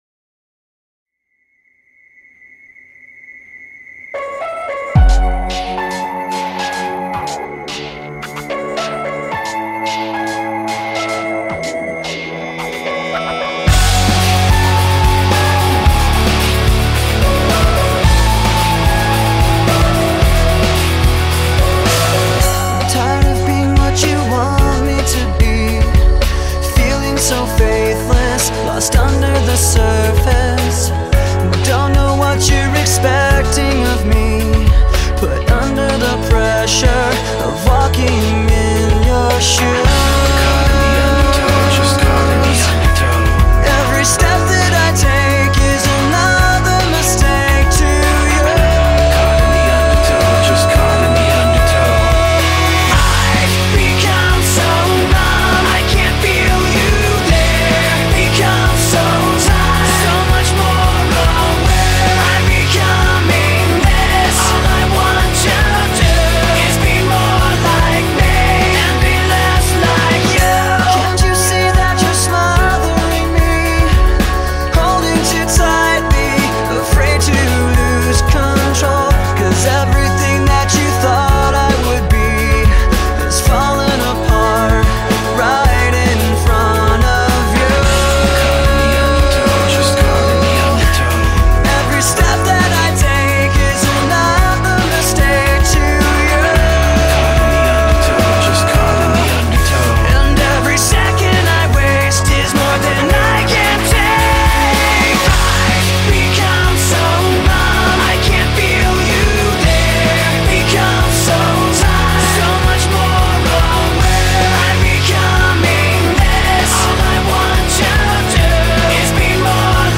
2024-02-18 20:53:00 Gênero: Rock Views